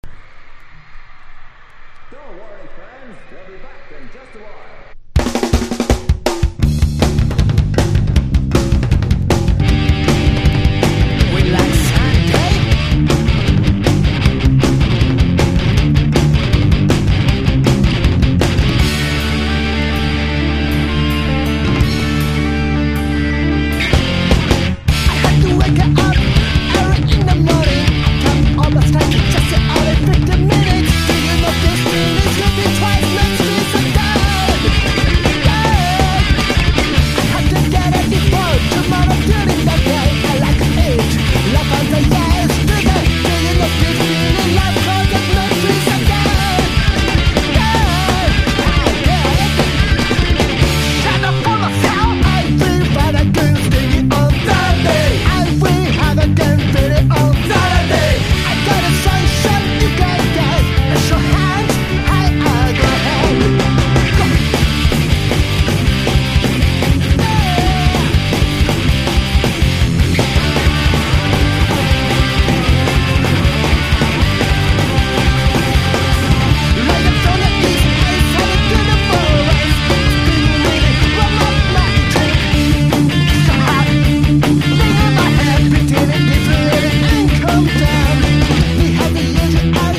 メロコア / スカパンク# PUNK / HARDCORE# 90’s ROCK# PUNK